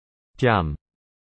韓国で「ほっぺ」のことを「뺨（ピャン）」と言います。